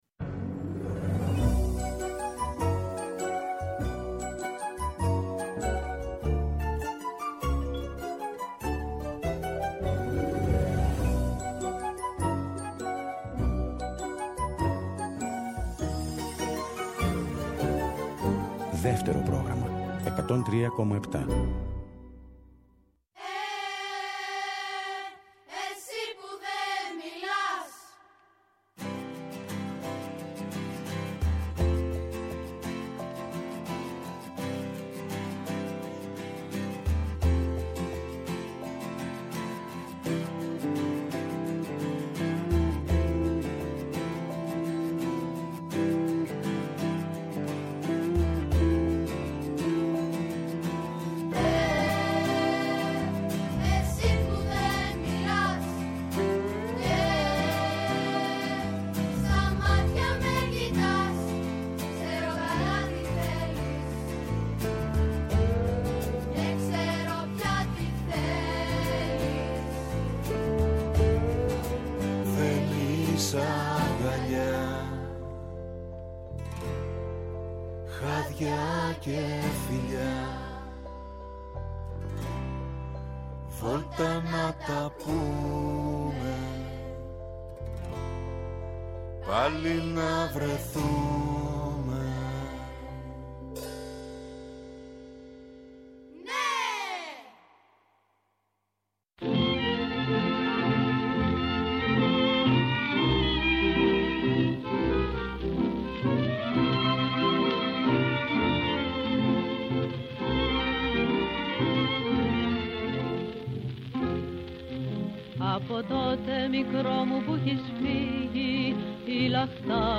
Λαϊκά τραγούδια που λένε ”σε περιμένω ή όχι πια..”